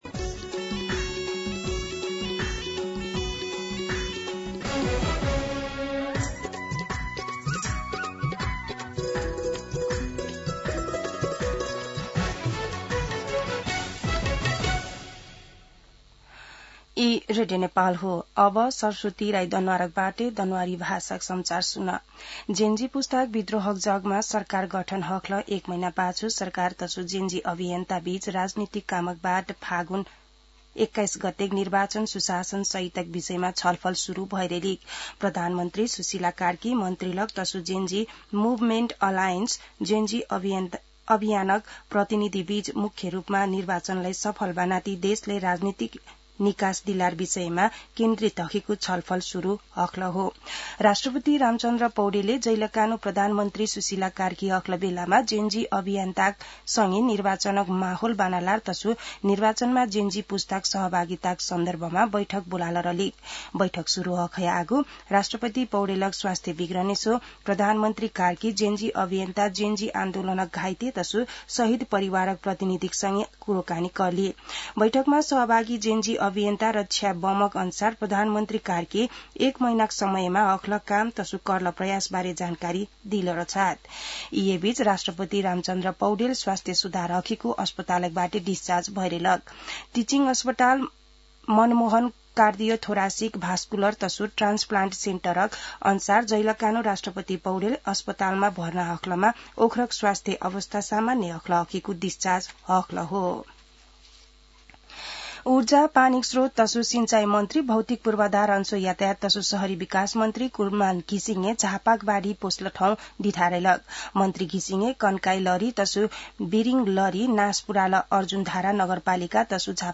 दनुवार भाषामा समाचार : २६ असोज , २०८२
Danuwar-News-.mp3